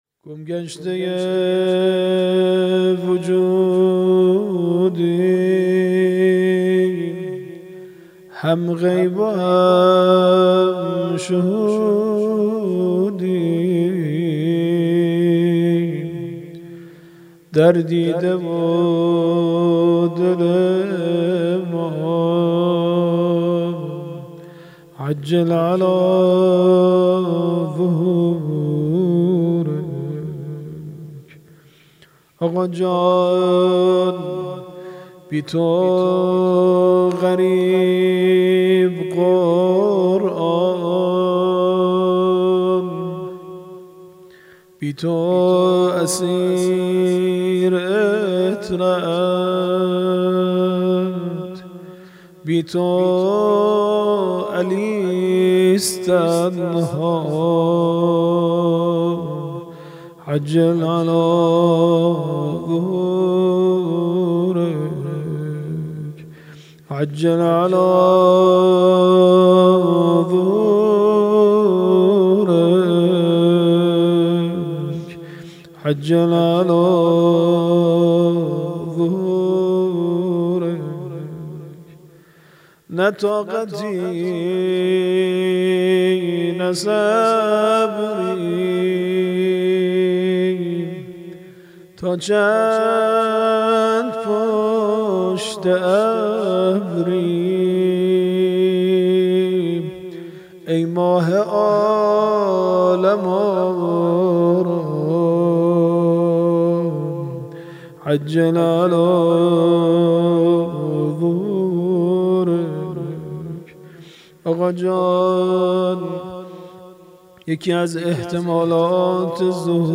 خیمه گاه - هیئت الزهرادانشگاه شریف - مناجات_سحر_ماه_مبارک_رمضان